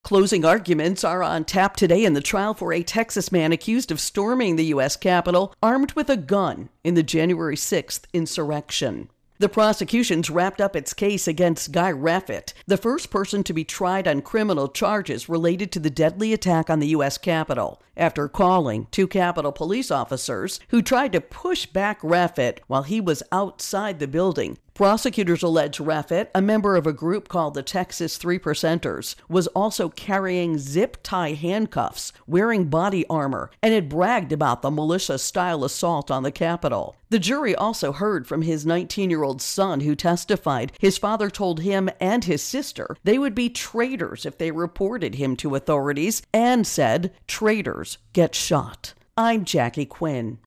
Capitol Riot First Trial Intro and Voicer